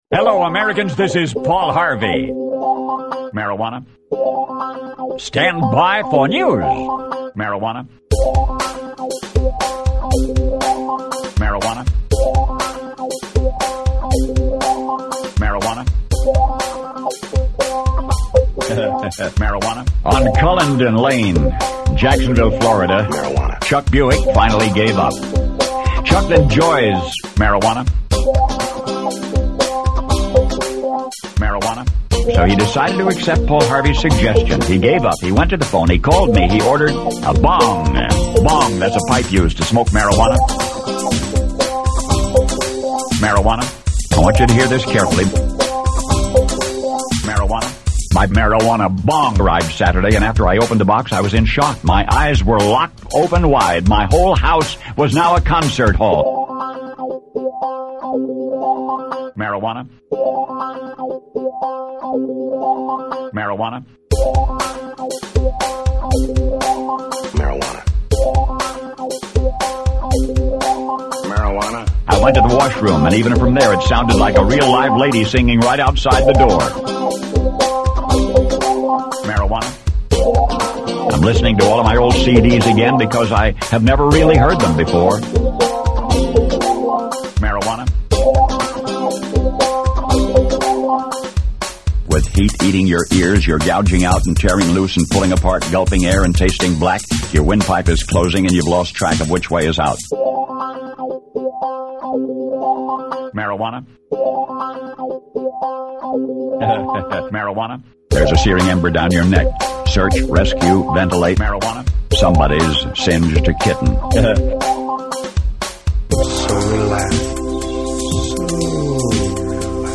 The proper credit here. though, goes not to Mr. Harvey, who just provided the fodder.
By the way, there exist two Paul Harvey collage/mashups, which is just part of the larger Celebrity Speech gallery you can find here.